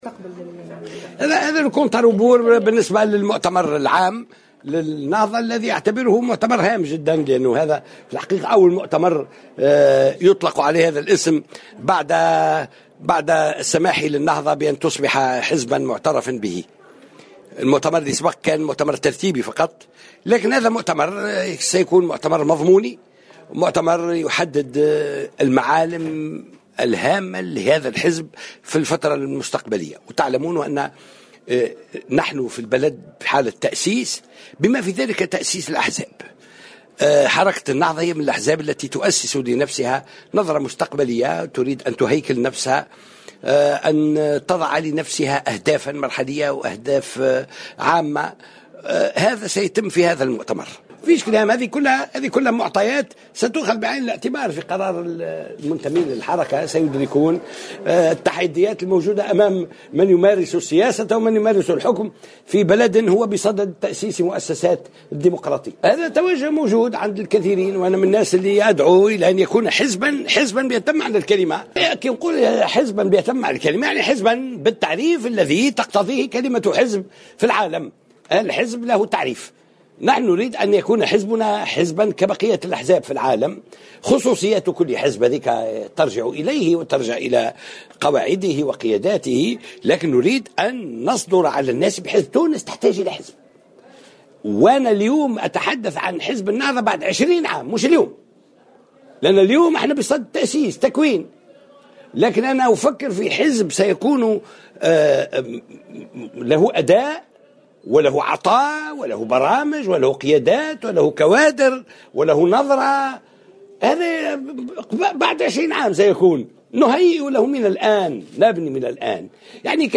أكد القيادي في حركة النهضة عبد الفتاح مورو، في تصريح لمراسلة الجوهرة أف أم، على هامش انعقاد المؤتمر الجهوي للحركة اليوم السبت بالحمامات، تأييده لجعل الحزب سياسيا 100 ٪ بتخليه عن أنشطته الدعوية، حتى يصبح قادرا على تقديم برامج تلبي تطلعات التونسيين.